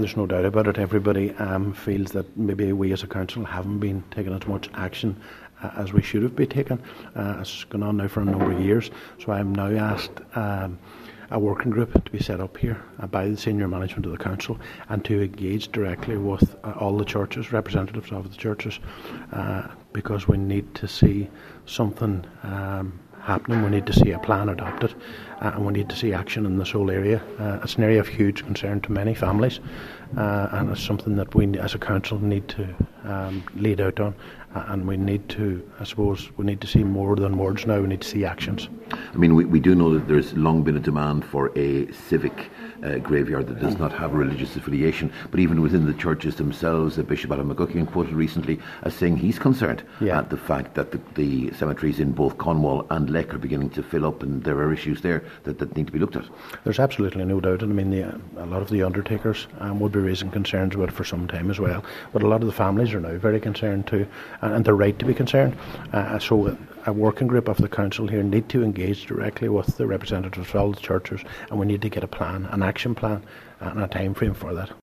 The issue has been raised at the Letterkenny Milford Municipal District on a number of occasions, but Cllr Brogan says it’s a countywide issue that demands a countywide approach: